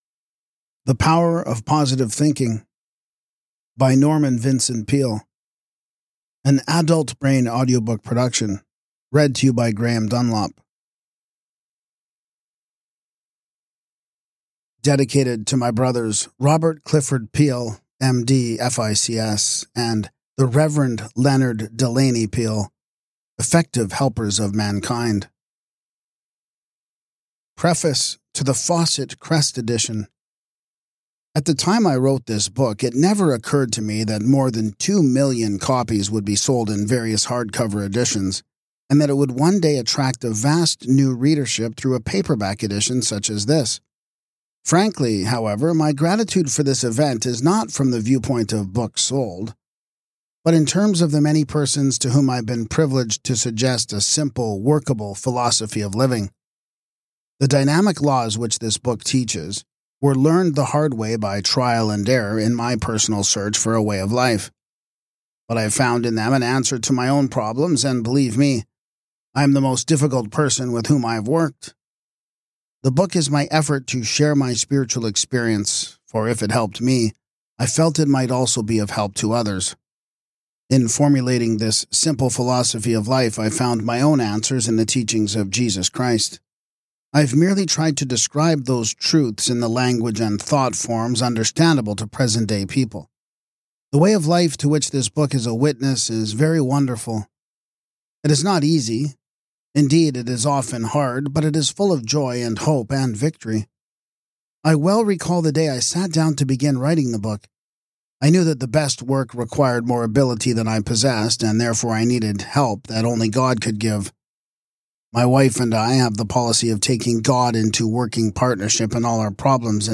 With compelling examples and actionable advice, this audiobook empowers listeners to: